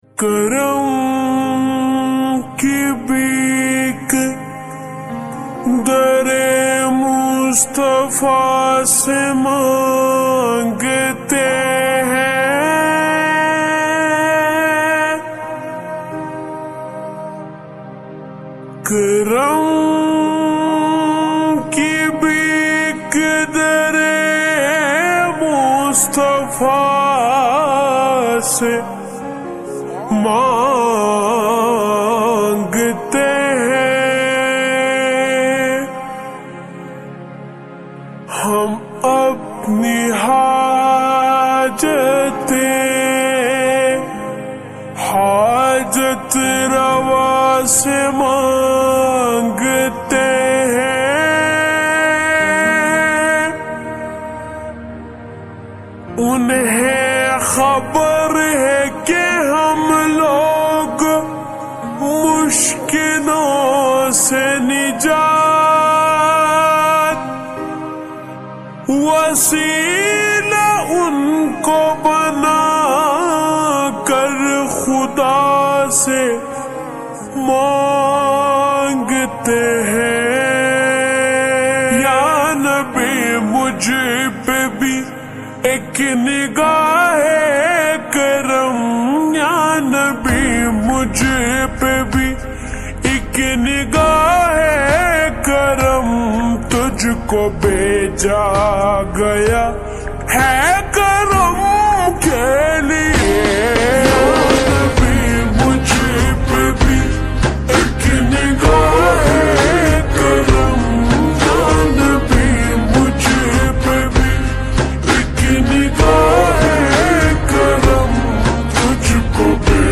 Slowed Reverb Naat